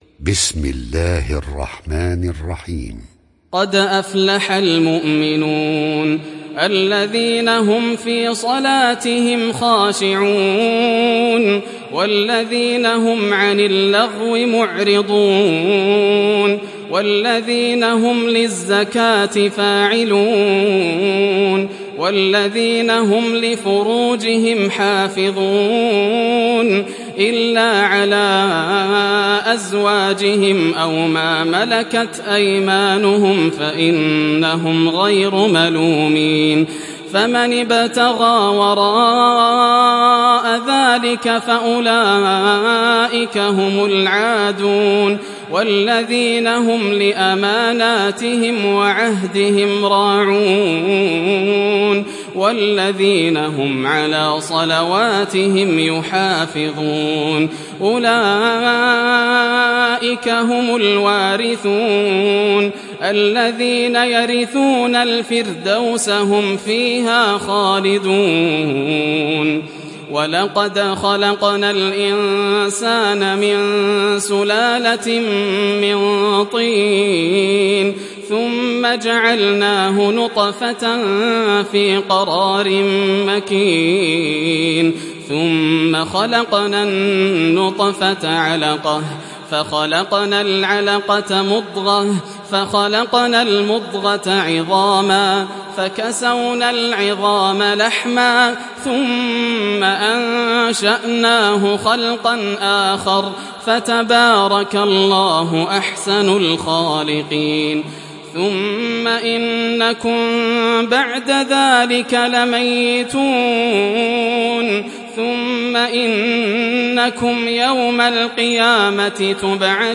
Muminun Suresi İndir mp3 Yasser Al Dosari Riwayat Hafs an Asim, Kurani indirin ve mp3 tam doğrudan bağlantılar dinle